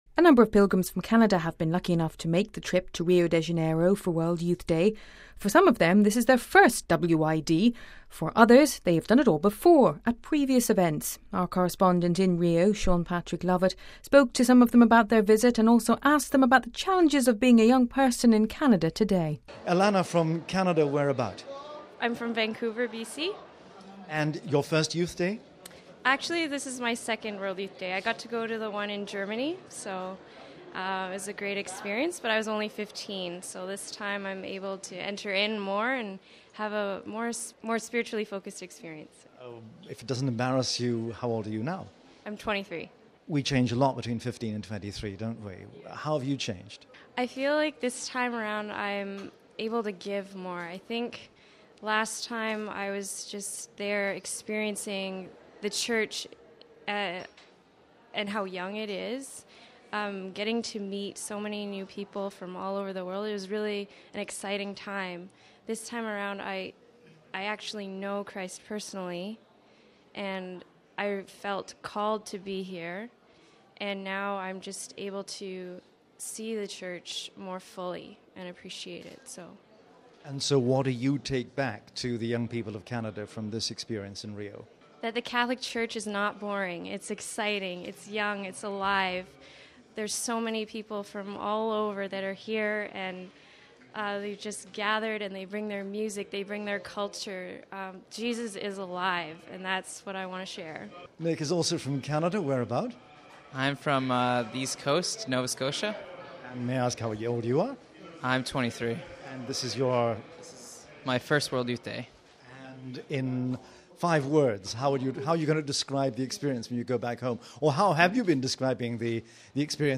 Our correspondent in Rio